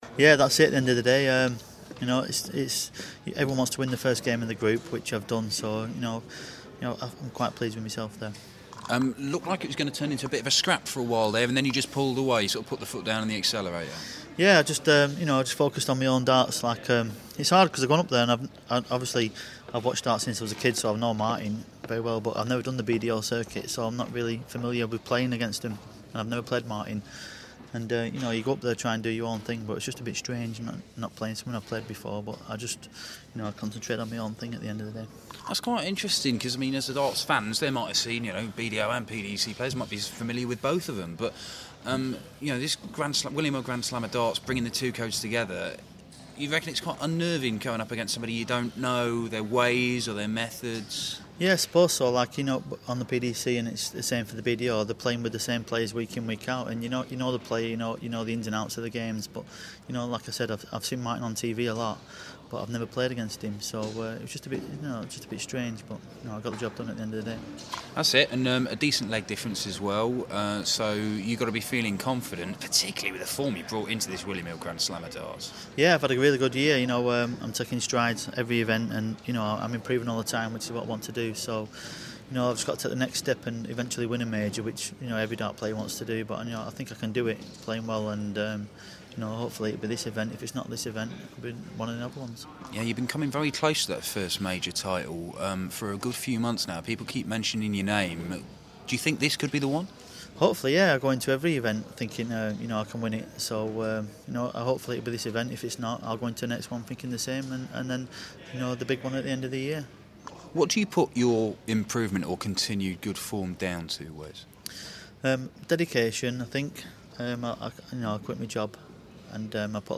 William Hill GSOD - Newton Interview